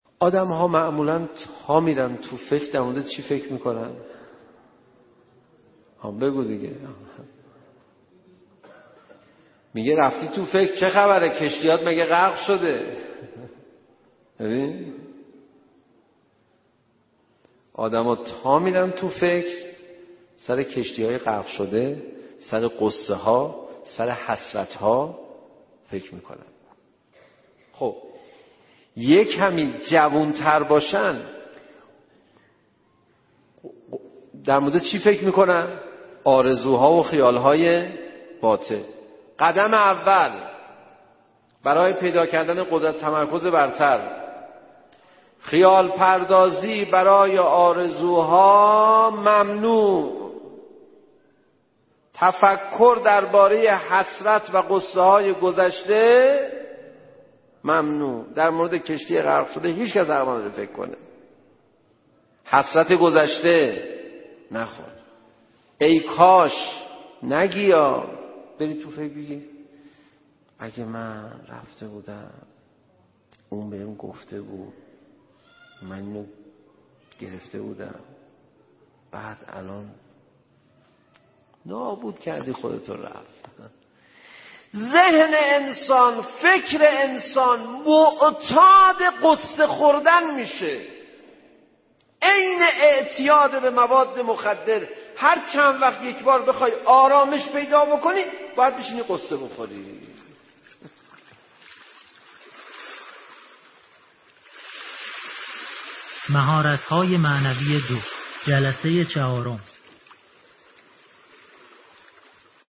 منبر دو دقیقه ای/معمولا ما فکر نمی کنیم ، حسرت می خوریم !